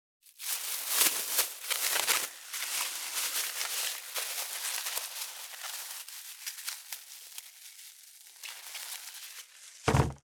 635コンビニ袋,ゴミ袋,スーパーの袋,袋,買い出しの音,ゴミ出しの音,袋を運ぶ音,
効果音